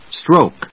/stróʊk(米国英語), strˈəʊk(英国英語)/